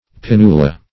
pinnula - definition of pinnula - synonyms, pronunciation, spelling from Free Dictionary Search Result for " pinnula" : The Collaborative International Dictionary of English v.0.48: Pinnula \Pin"nu*la\, n.; pl.